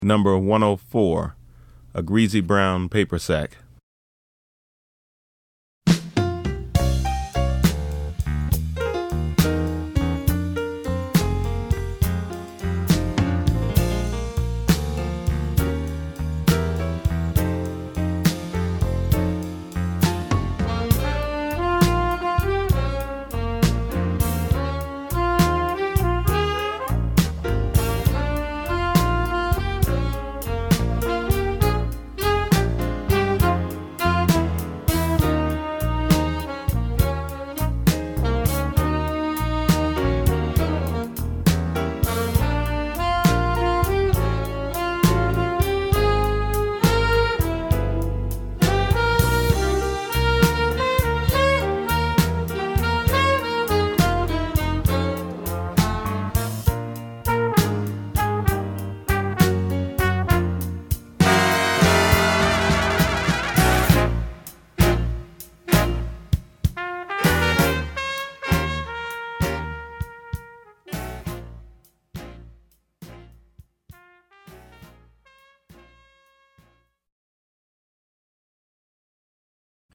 • 5 Saxophones
• 4 Trumpets
• 4 Trombones
• Vibraphone
• Guitar
• Piano
• Bass
• Drums